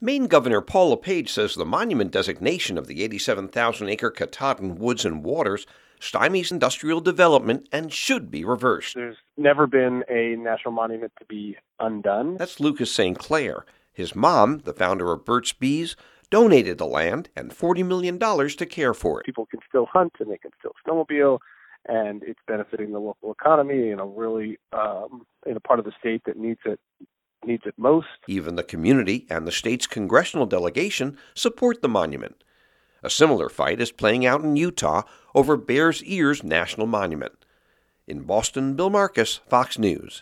7:00PM NEWSCAST